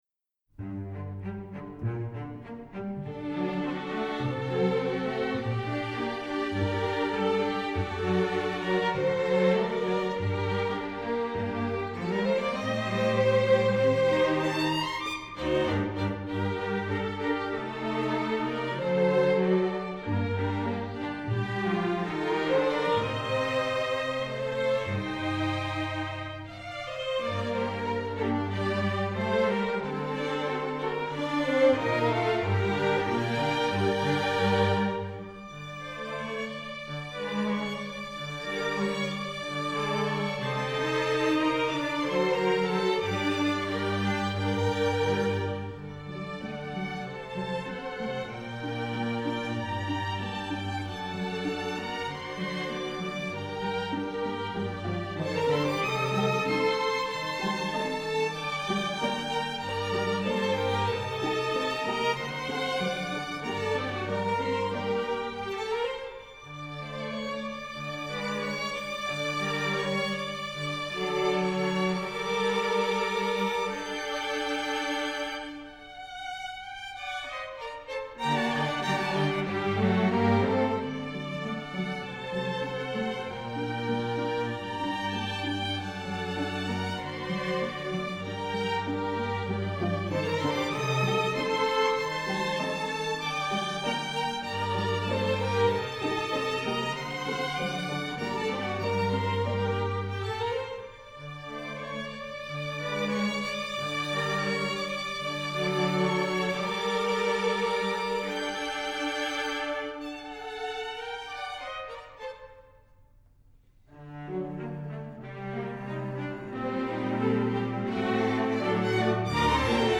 Composer: Russian Folk Song
Voicing: String Orchestra